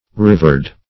Rivered \Riv"ered\, a.
rivered.mp3